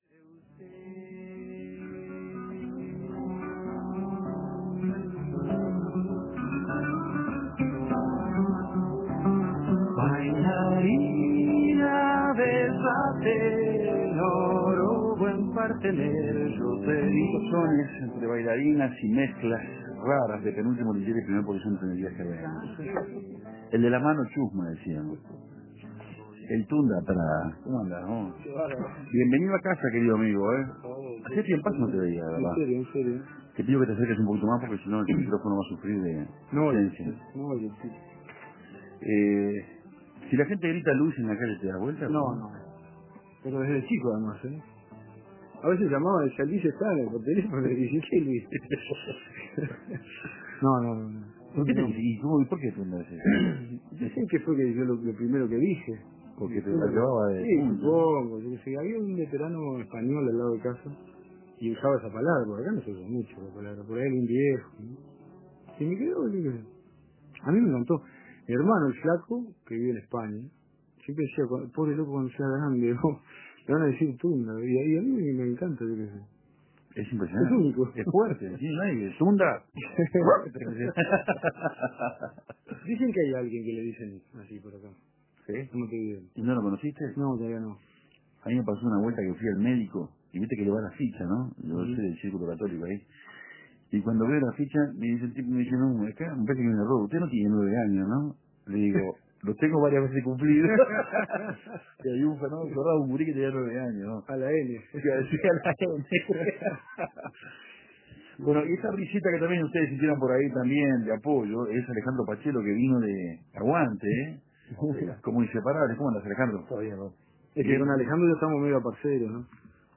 Sonidos crudos, dibujos en vivo...